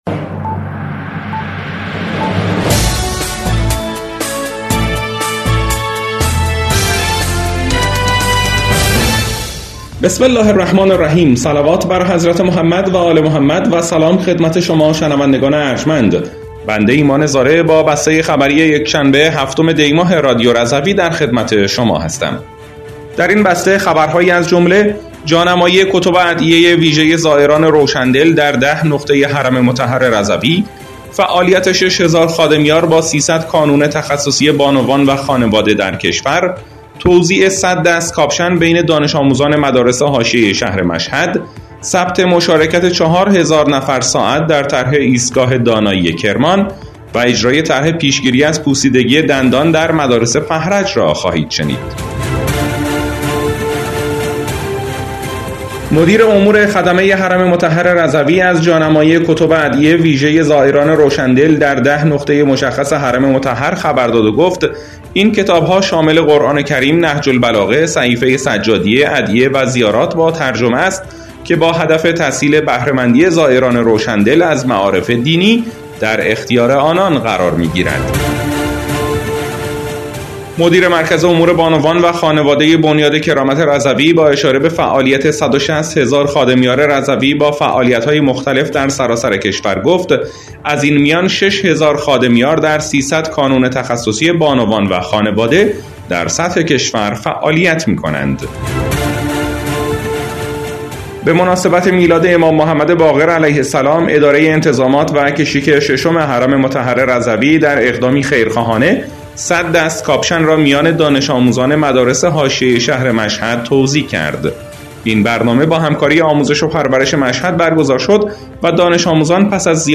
بسته خبری ۷ دی ۱۴۰۴ رادیو رضوی؛